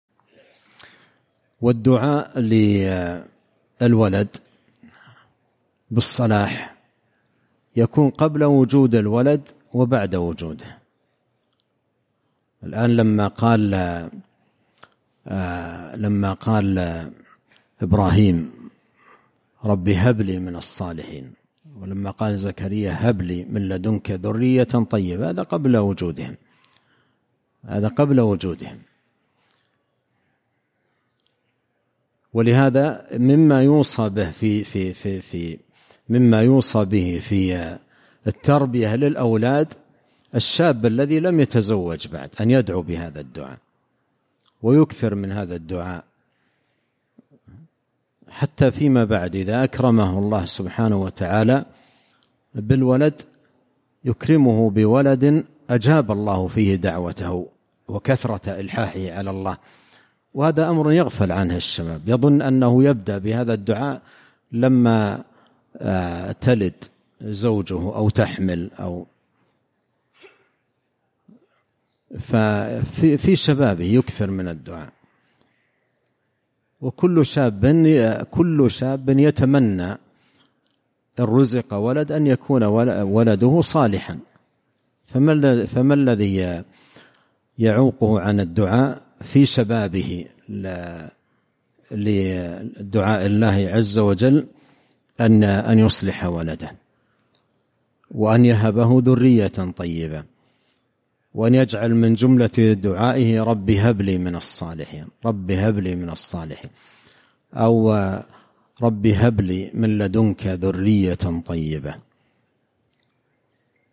🎙مقتطف من محاضرة قيمة بعنوان :